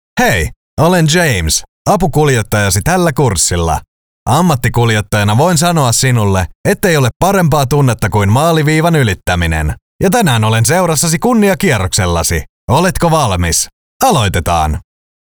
Junge, Cool, Corporate
E-learning